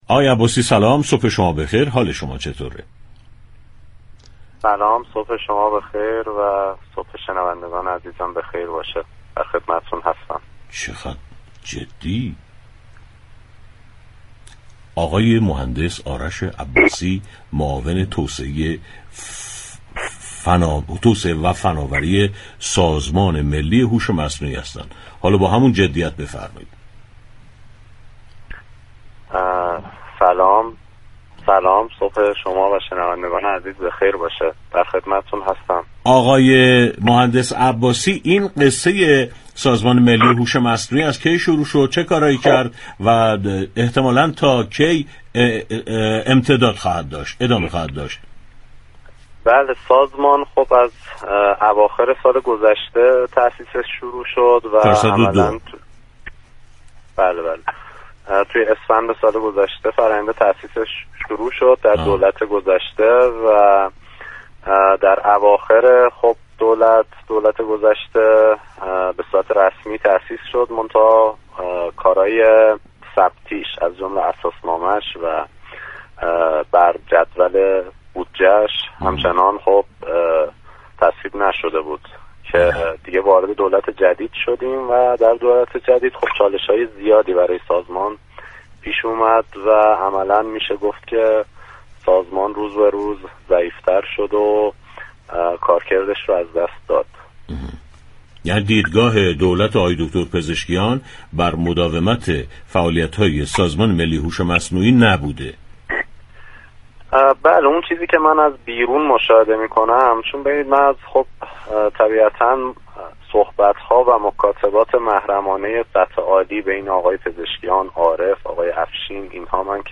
معاون توسعه و فناوری سازمان ملی هوش مصنوعی در گفت و گو با رادیو تهران با بیان اینكه آخرین روز كاری خود در این سازمان را می‌گذراند، اظهار داشت: در دولت جدید، چالش‌های زیادی برای سازمان پیش آمد و در نهایت این سازمان انقدر ضعیف شد كه كاركرد خود را از دست داد.